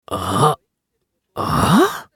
男性
熱血系ボイス～日常ボイス～